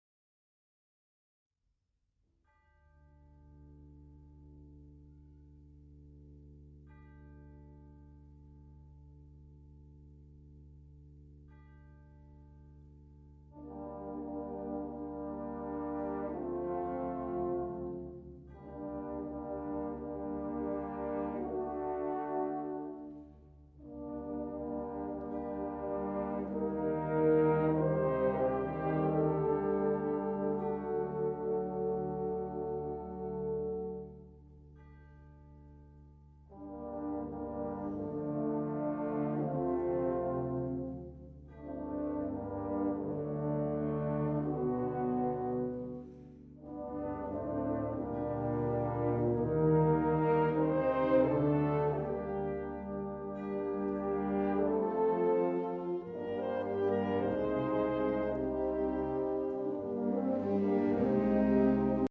symphonic band